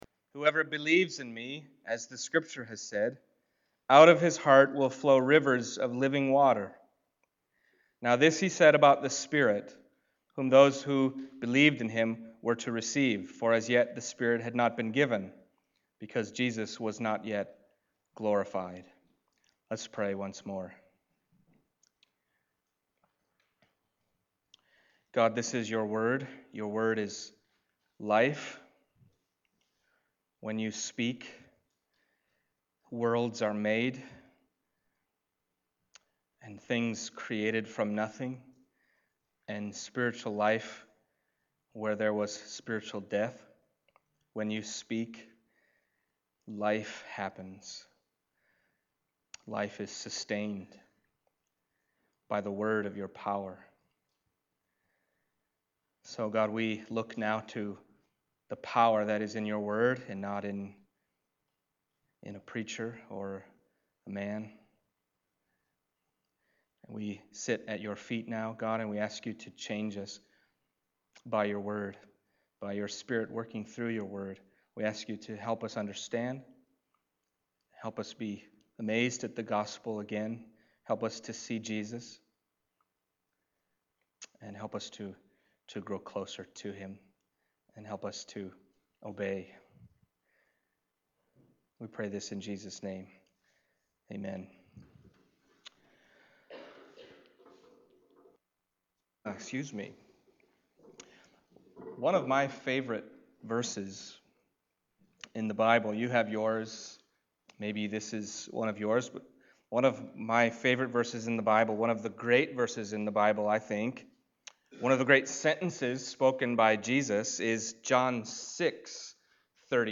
John Passage: John 7:37-39 Service Type: Sunday Morning John 7:37-39 « Wholeness That Will Last Forever Justice and Mercy